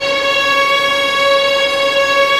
Index of /90_sSampleCDs/Roland L-CD702/VOL-1/STR_Vlns Bow FX/STR_Vls Sul Pont